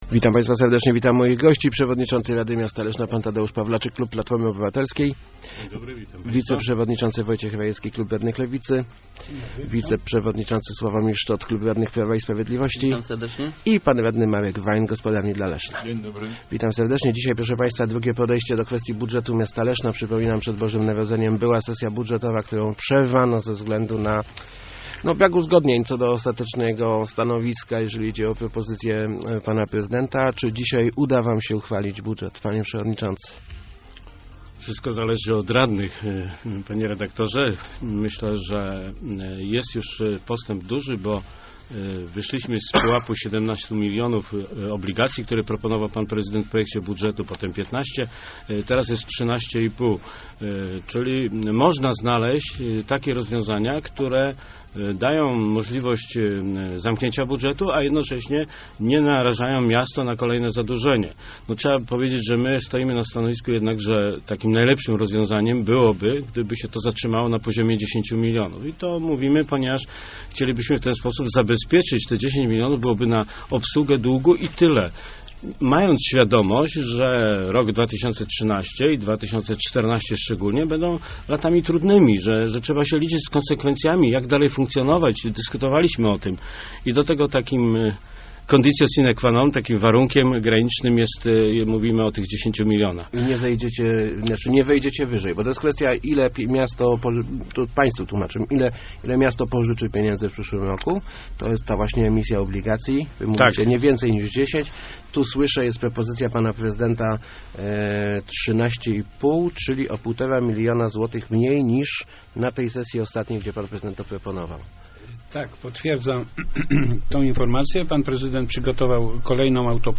Uchwalenie budżetu Leszna wcale nie jest takie pewne. Wciąż nie ma zgody co do wielkości zadłużenia miasta. Ostatnia propozycja prezydenta to 13,5 miliona obligacji - mówił w Rozmowach Elki Wojciech Rajewski (Lewica) - o 3,5 mniej niż w pierwotnej wersji.